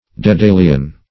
Dedalian \De*dal"ian\, a.